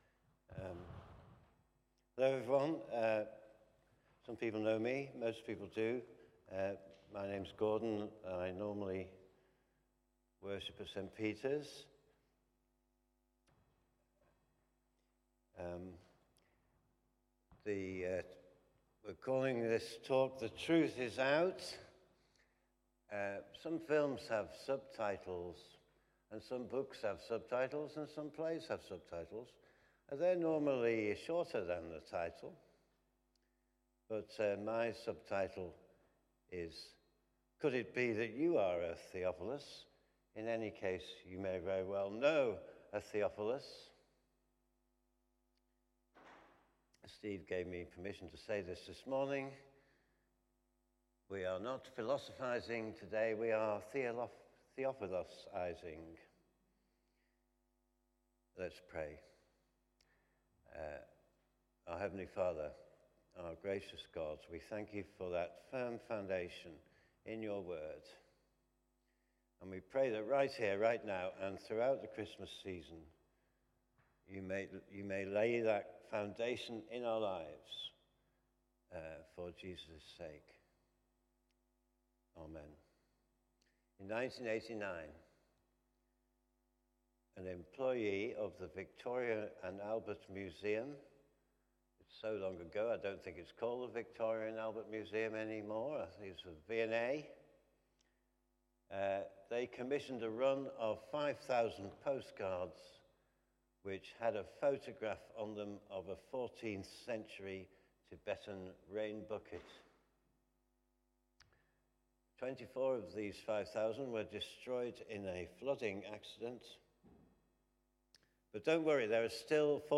Media Library The Sunday Sermons are generally recorded each week at St Mark's Community Church.
The truth is out Sermon